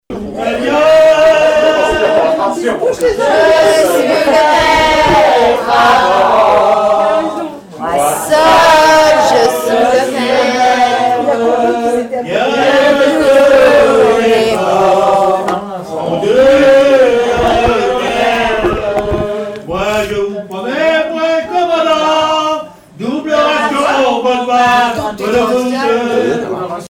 Fécamp
Chansons et commentaires
Pièce musicale inédite